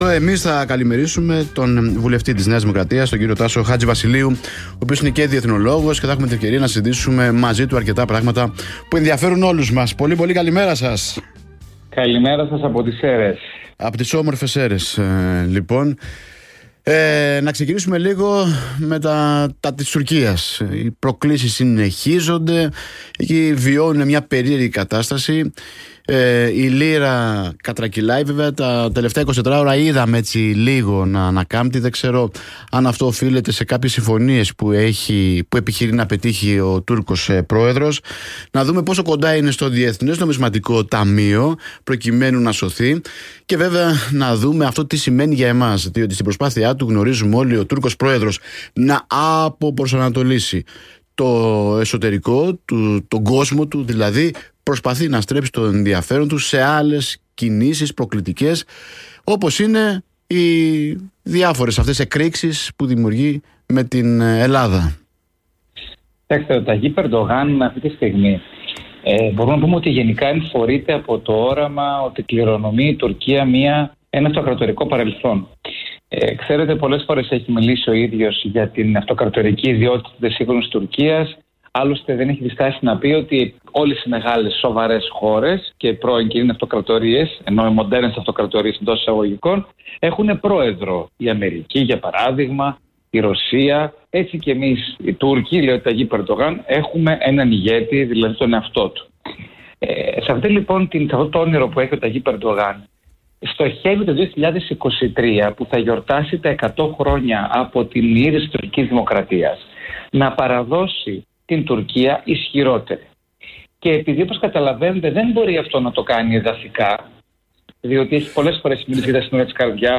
Ο βουλευτής Ν. Σερρών της ΝΔ Τάσος Χατζηβασιλείου στην ραδιοφωνική εκπομπή “Καλή παρέα”